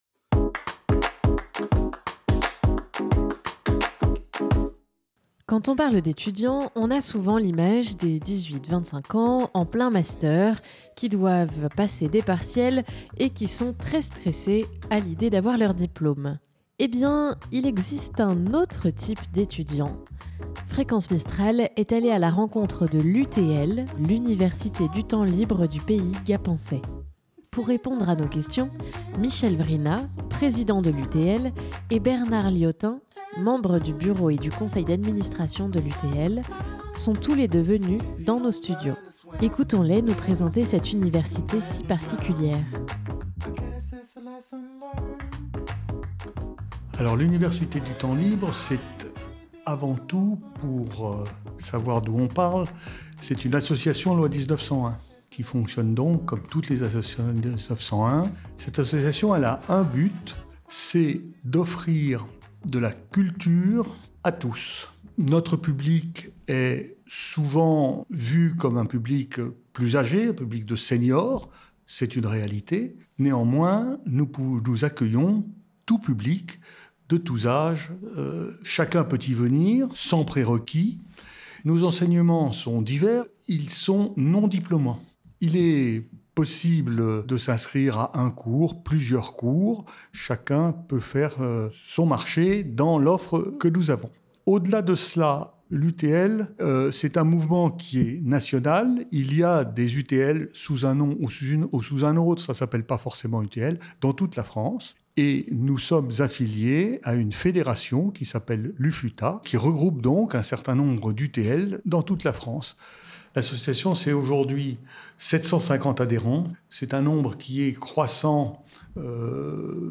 sont venus dans nos studios nous présenter l'UTL et ses défis.